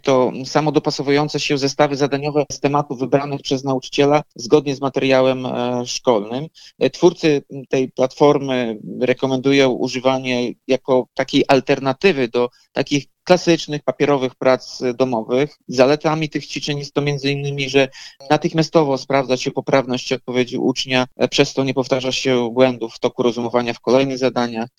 – mówi Tomasz Andrukiewicz prezydent Ełku.